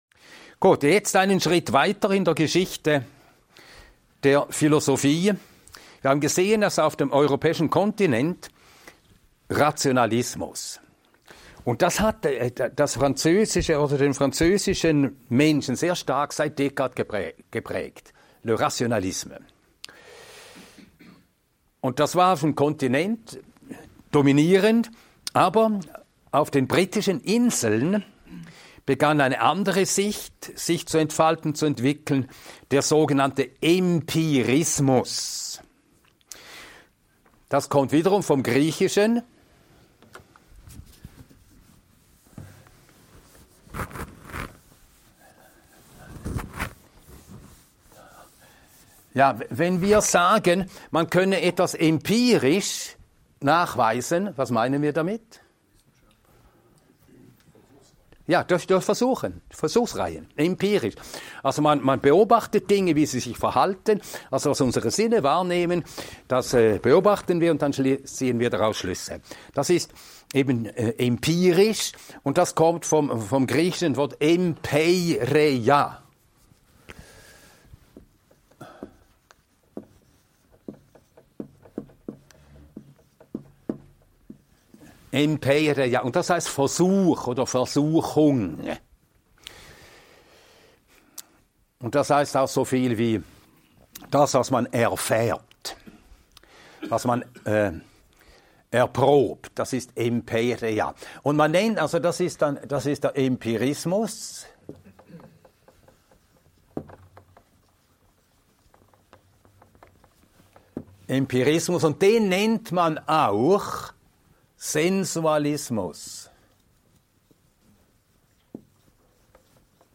In diesem Vortrag erfährst du, wie der Empirismus Englands unser Gottesbild und das Christentum geprägt hat – und warum daraus ein Aktivismus entstand, dem oft die Glaubenssubstanz fehlt. Lerne, wie der Deismus Gott auf einen fernen Schöpfer reduziert und entdecke, welche fünf Glaubenssätze daraus entstanden. 00:00 Was ist Empirismus?